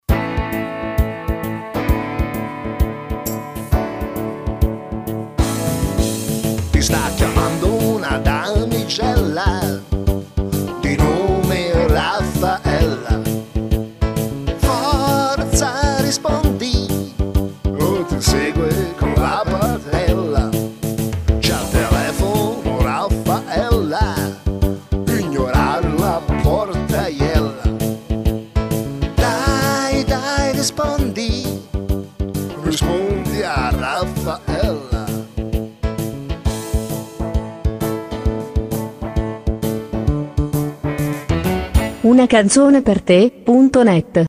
Una suoneria personalizzata